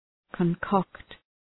{kɒn’kɒkt}
concoct.mp3